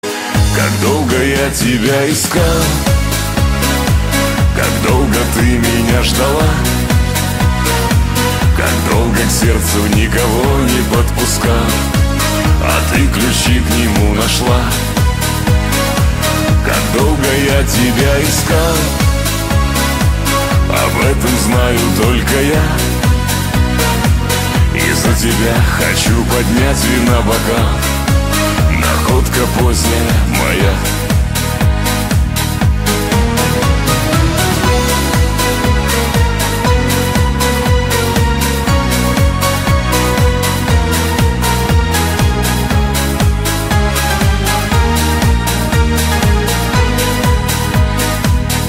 • Качество: 256, Stereo
мужской вокал
душевные
русский шансон